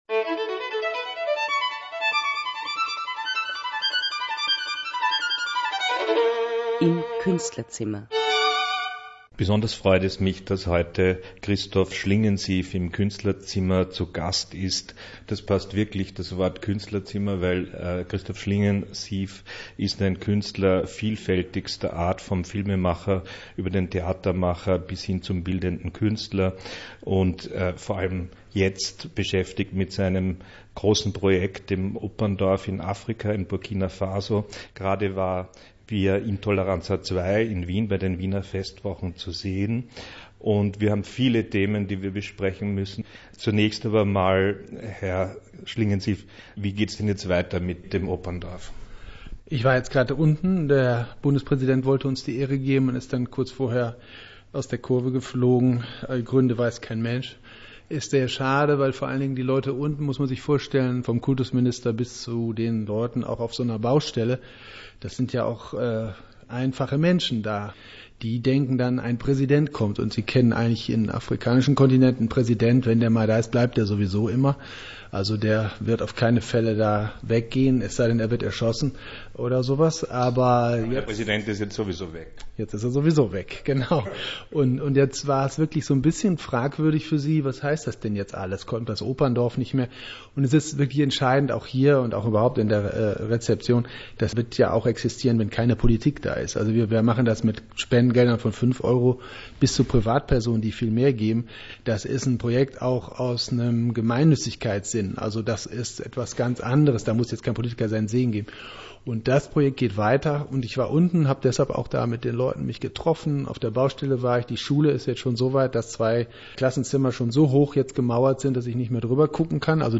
Christoph Schlingensief im Interview zu VIA INTOLLERANZA II